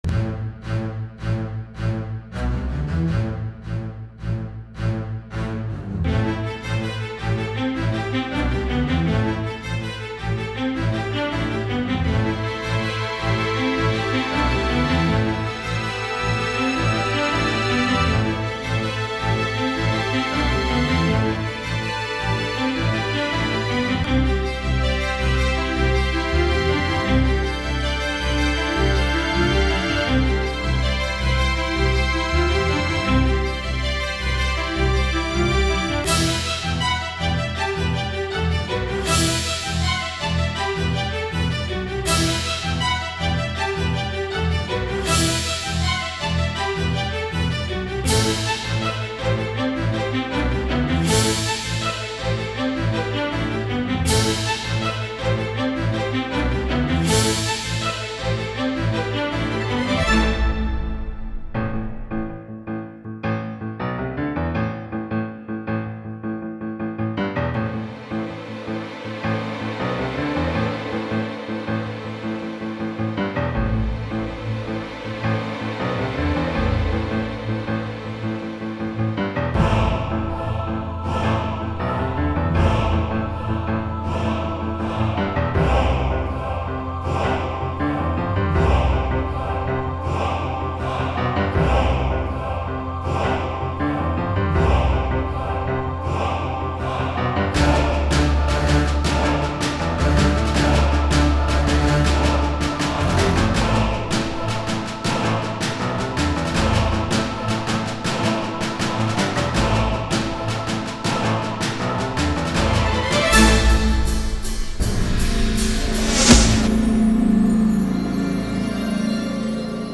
ορχηστρικές συνθέσεις